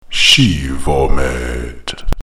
shee-vaw-meht